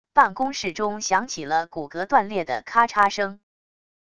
办公室中响起了骨骼断裂的咔嚓声wav音频